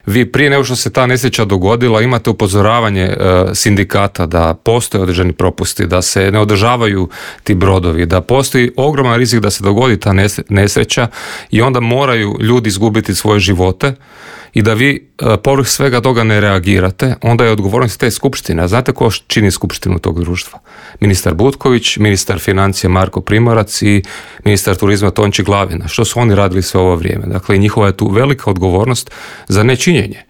O svemu smo u Intervjuu Media servisa razgovarali sa saborskim zastupnikom SDP-a Mihaelom Zmajlovićem.